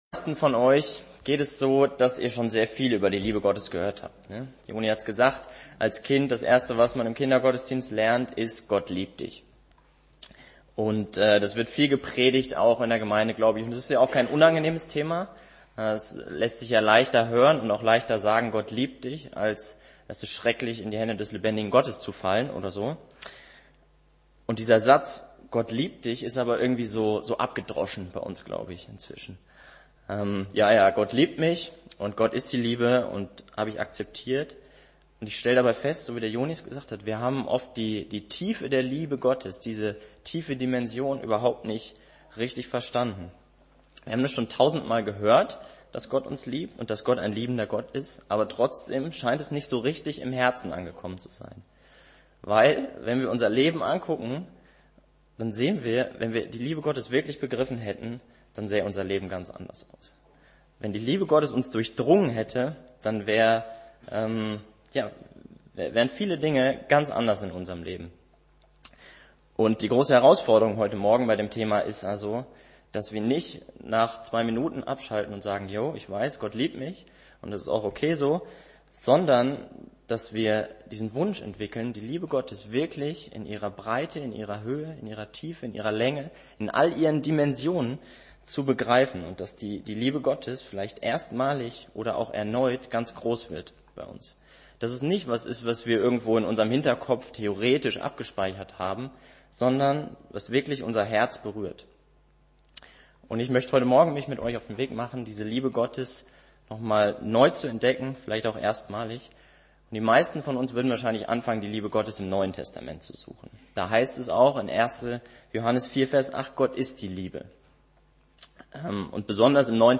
Nach der Predigt wurde folgendes Video gezeigt: Die Brücke